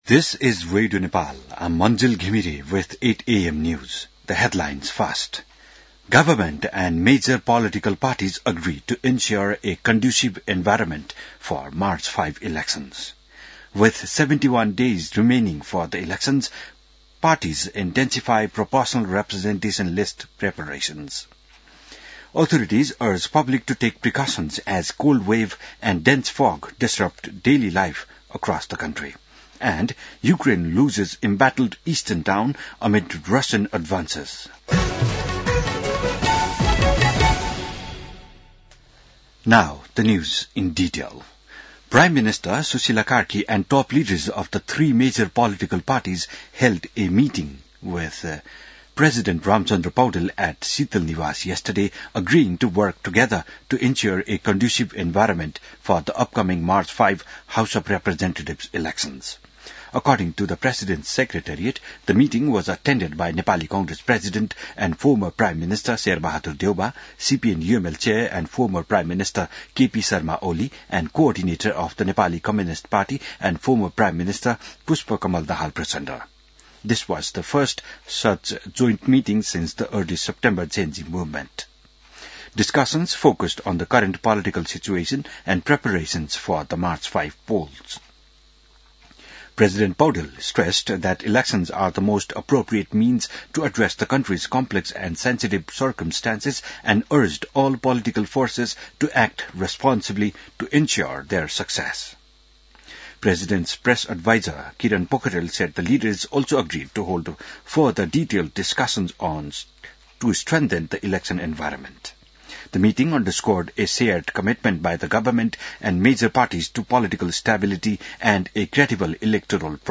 बिहान ८ बजेको अङ्ग्रेजी समाचार : ९ पुष , २०८२